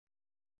♪ danidōṛu